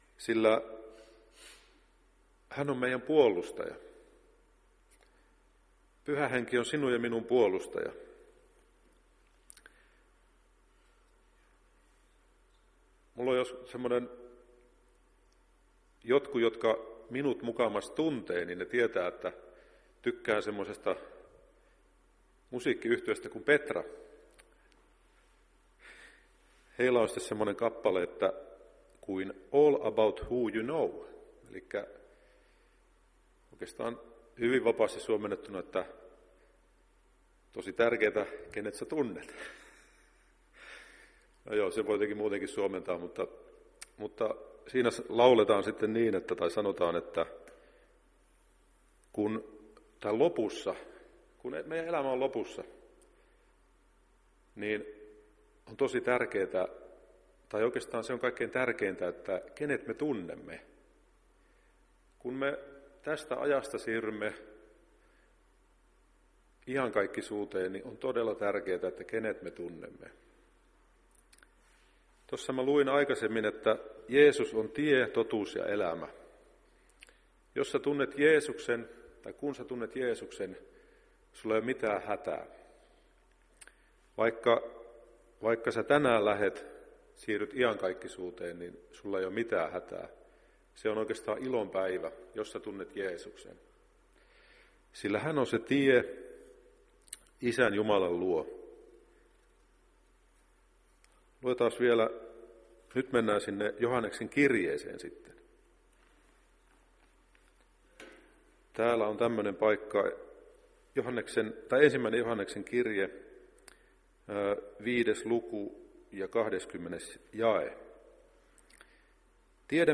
Hyvänsanoman iltapäivä 25.4.2021(LIVE)